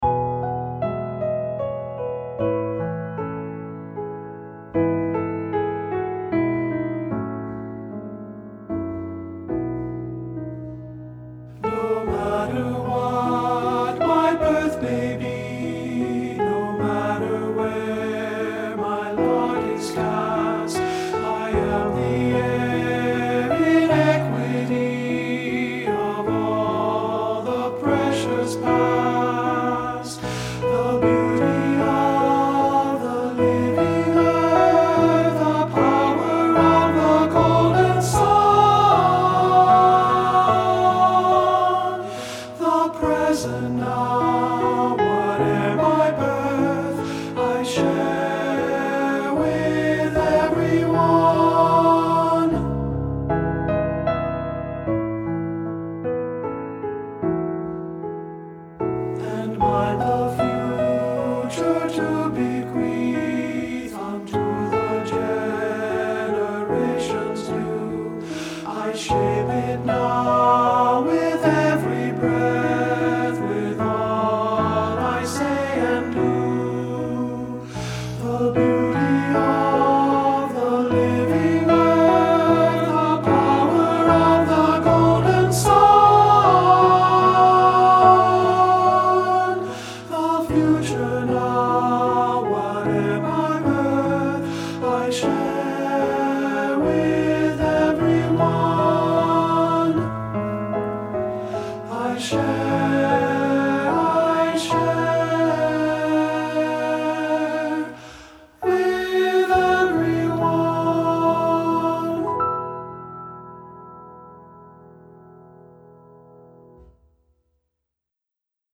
Unison/Optional Two-Part Unchanged Voices with Piano
• Piano
Ensemble: Tenor-Bass Chorus
Accompanied: Accompanied Chorus